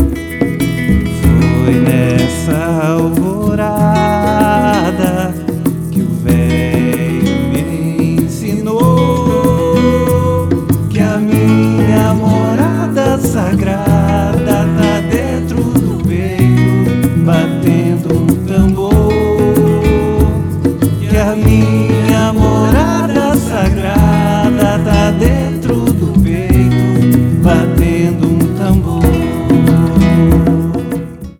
Backing Vocals
Violões
Contrabaixo
Percussão
Ganzá